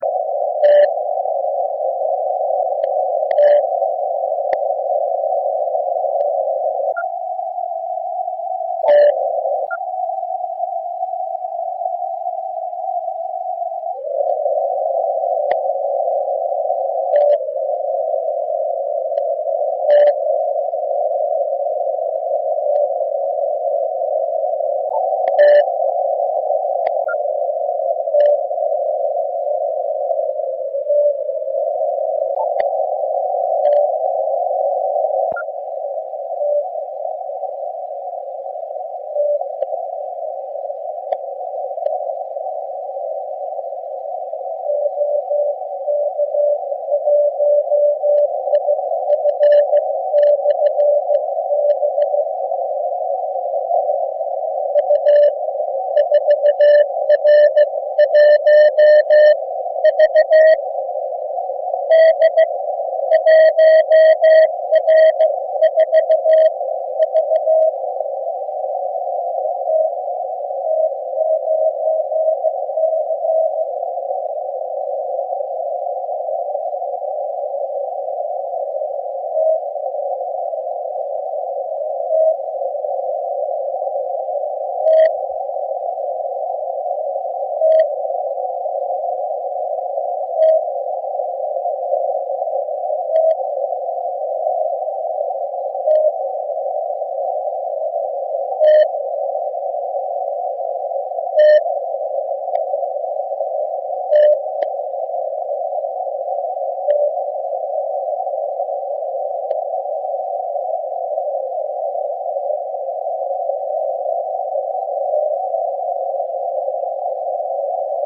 U antény jsem nechal ts590.
Odpoledne byl signál parádní, posuď sám.